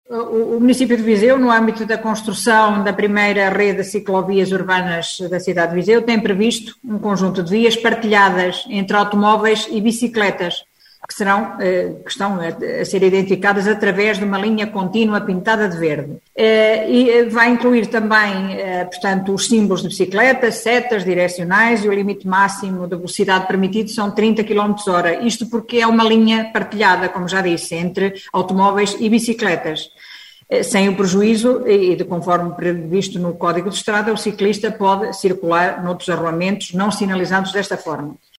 Em declarações aos jornalistas, Conceição Azevedo explicou que os primeiros quilómetros da ciclovia são na cidade e estão a ser assinalados com uma linha contínua pintada de verde.